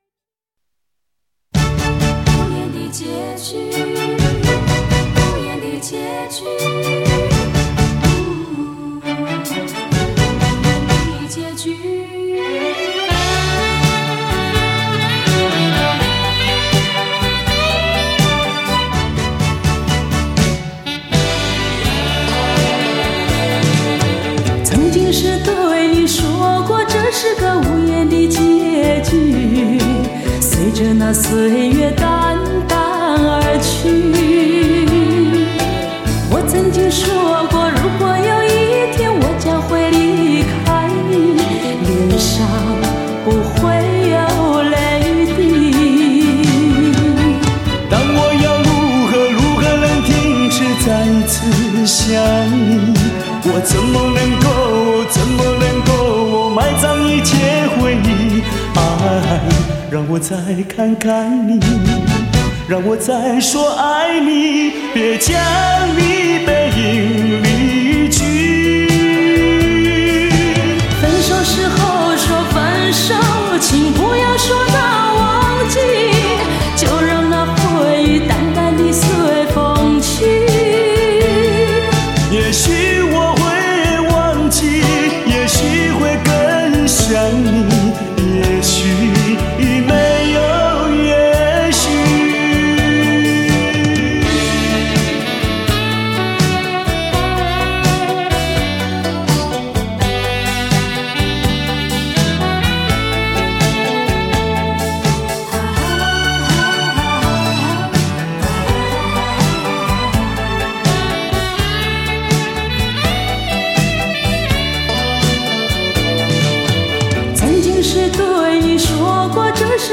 震天动地的强劲动感 举座皆惊的靓绝旋律